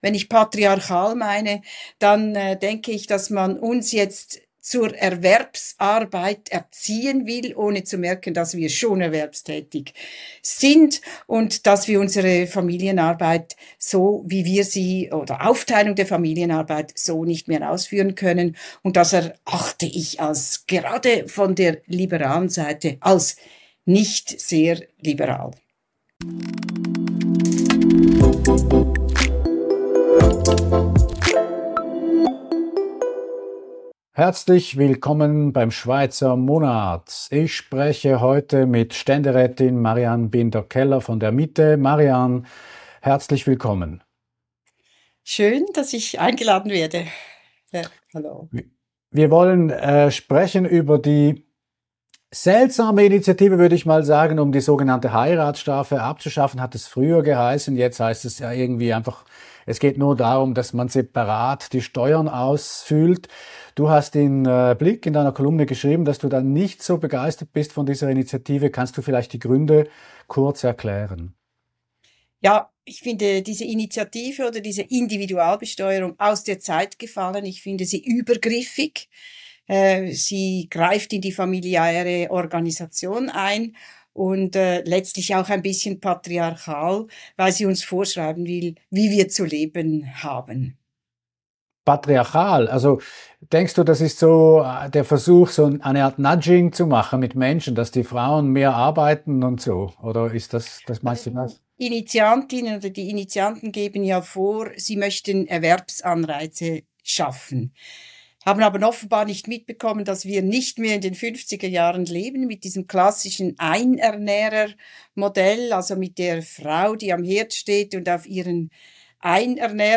im Gespräch mit Ständerätin Marianne Binder-Keller (Die Mitte).